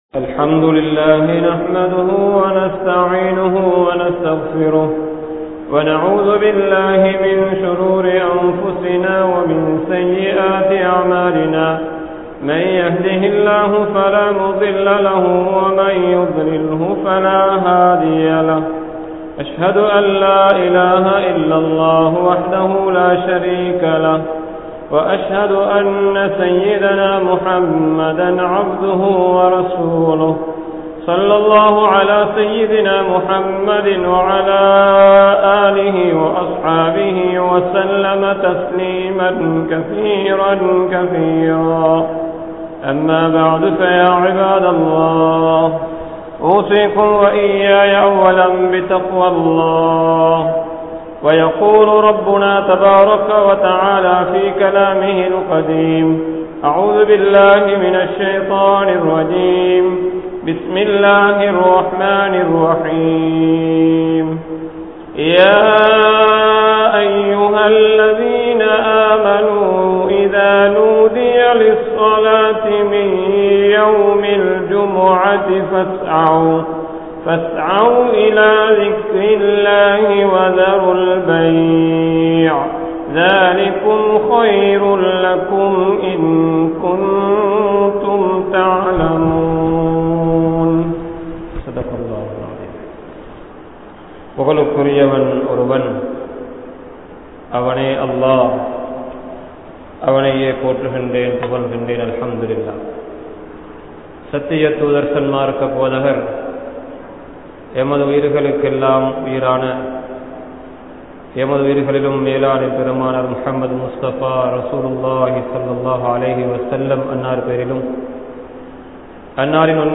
Virtues Of Jumuah | Audio Bayans | All Ceylon Muslim Youth Community | Addalaichenai